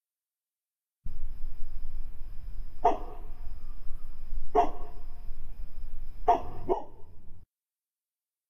I just got my new mic and I was messing around with it so I uploaded some sounds that I recorded, Eqed, and made into stereo.1. Cricket Ambience2.
dog barking.ogg